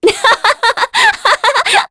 Aselica-Vox_Happy3_kr.wav